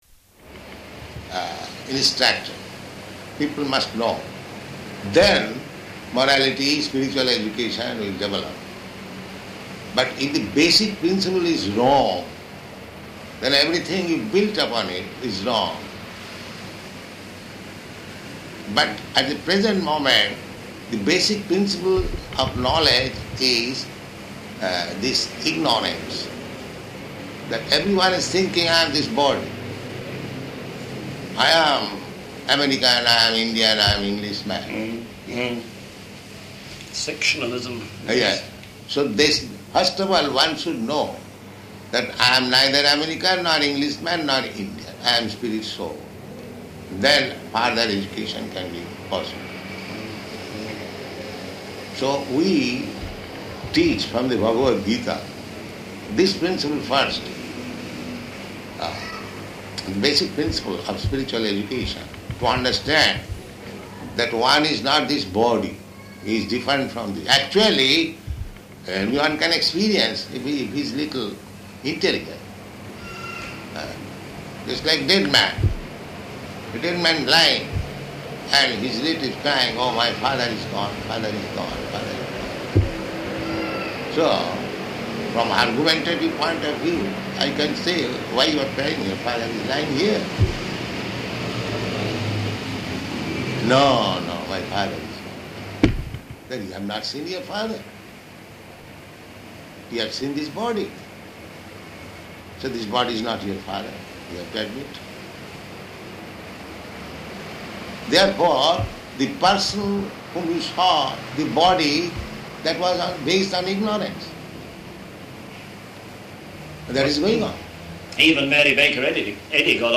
Room Conversation
Room Conversation --:-- --:-- Type: Conversation Dated: August 9th 1972 Location: London Audio file: 720809R1.LON.mp3 Prabhupāda: ...instructed, people must know.